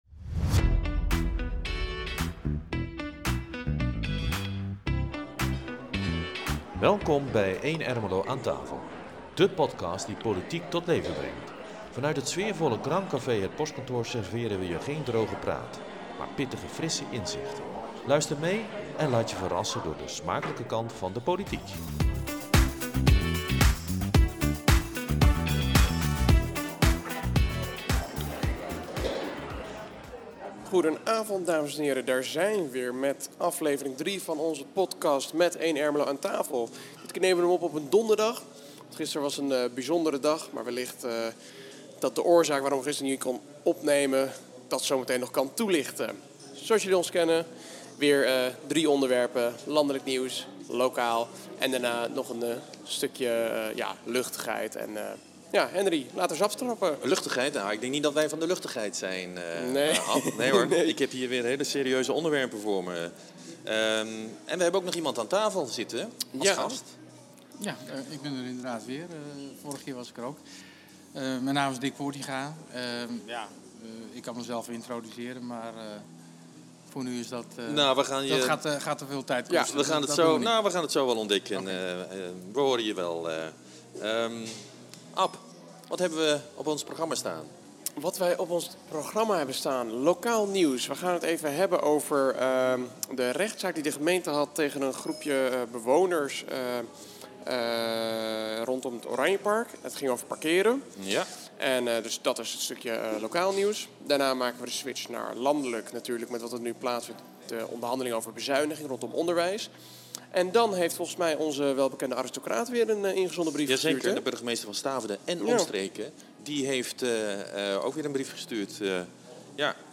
Beluister onze Podcast met één-Ermelo aan tafel vanuit het Postkantoor